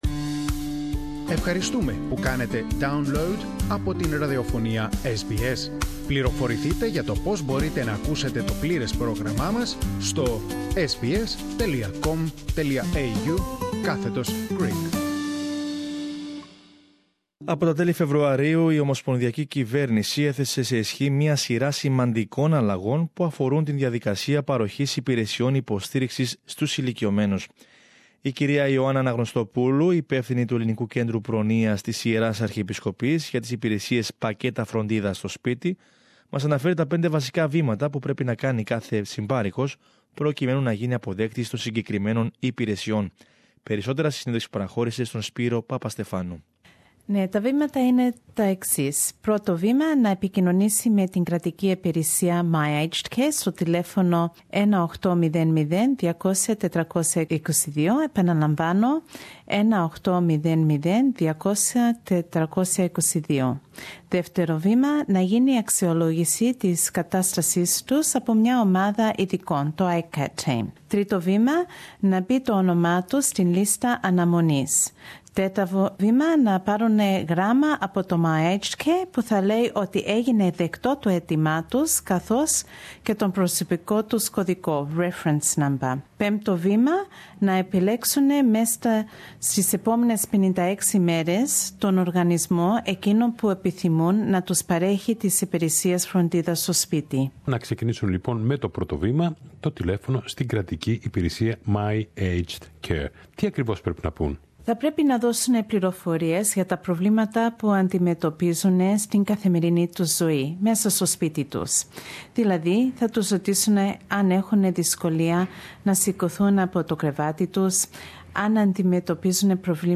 Περισσότερα στην συνέντευξη